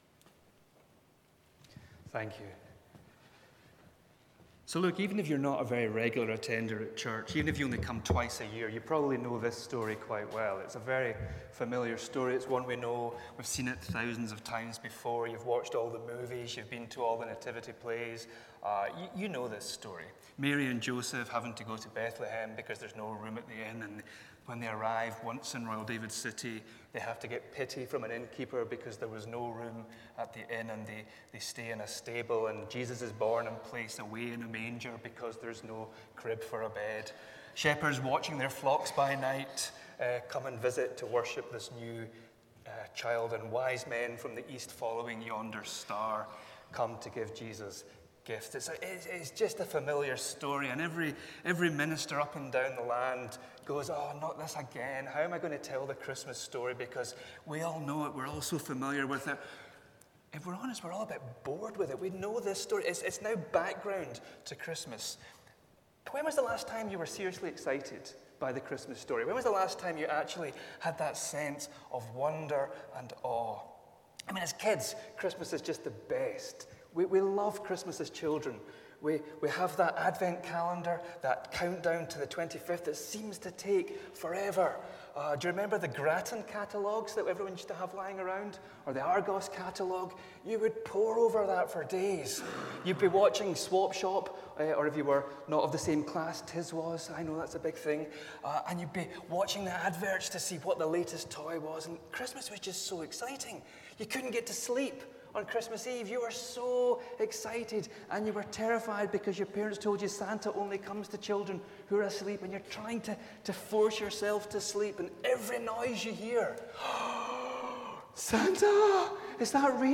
Passage: Luke 2:1-20 Service Type: Sunday Morning It’s Christmas Day today.